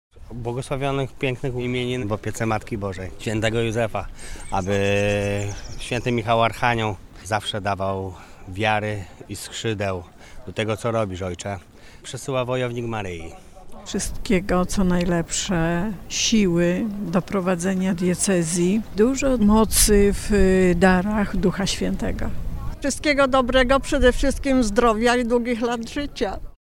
Z tej okazji życzenia Księdzu Arcybiskupowi składają wierni archidiecezji.